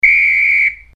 Referi silbato